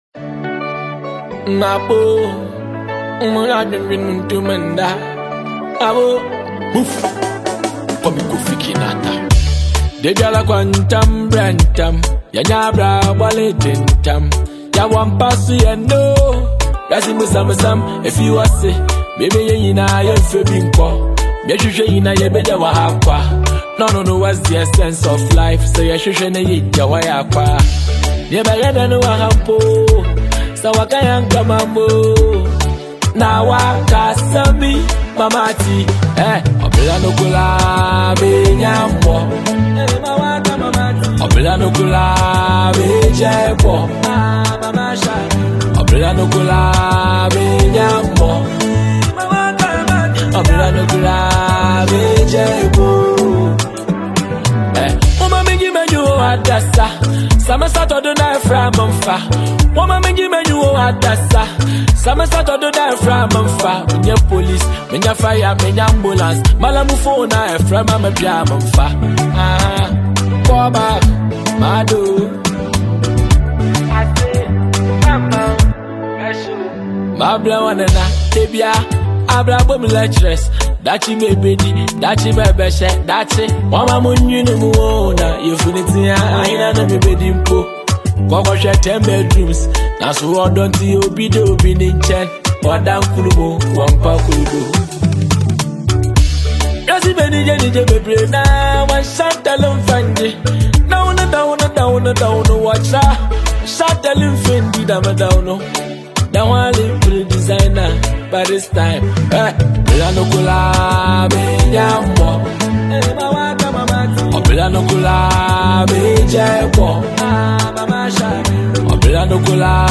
giving fans a meaningful and reflective tune.
smooth production